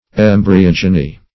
Search Result for " embryogeny" : The Collaborative International Dictionary of English v.0.48: Embryogeny \Em`bry*og"e*ny\, n. [Gr.